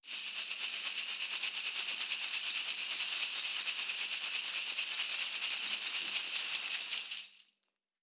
Danza árabe, bailarina haciendo el movimiento shimmi 01
continuo
moneda
Sonidos: Acciones humanas